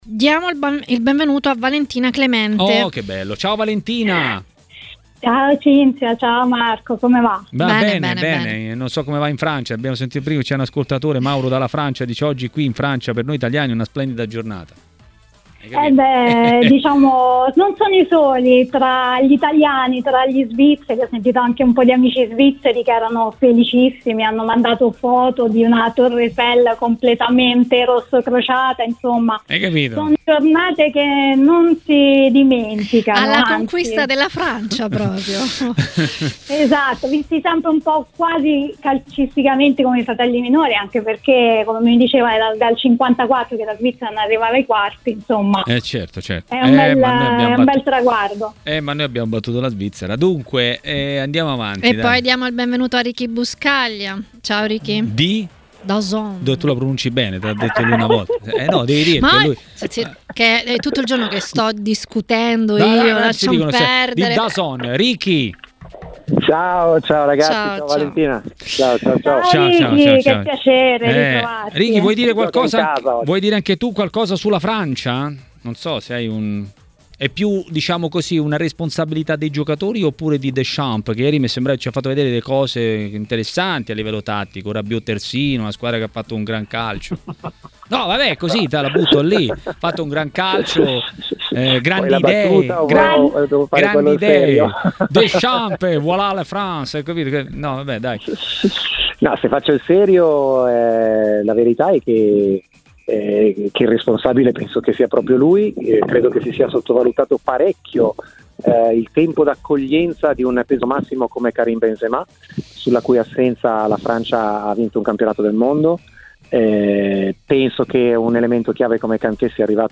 Ascolta l'audio A parlare di Europei a TMW Radio, durante Maracanà, è stato mister Gianni Di Marzio .